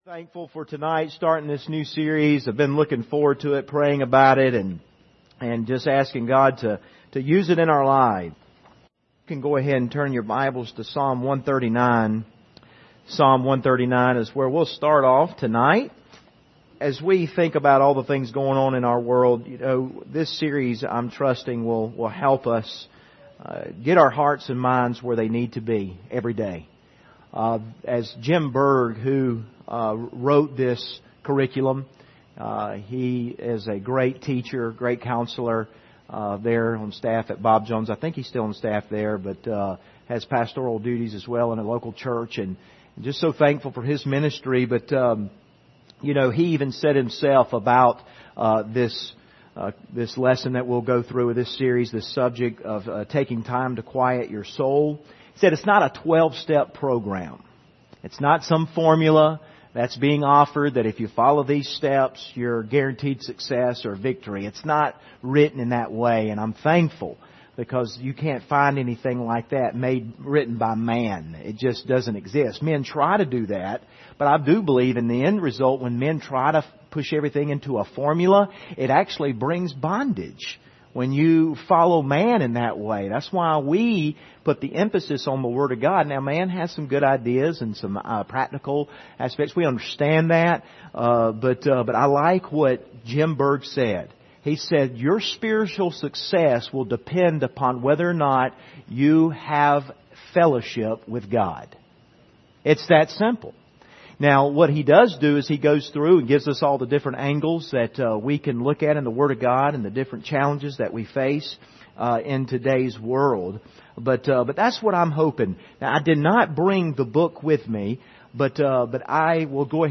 Quieting a Noisy Soul Service Type: Wednesday Evening « Time To Step Up Onward I Go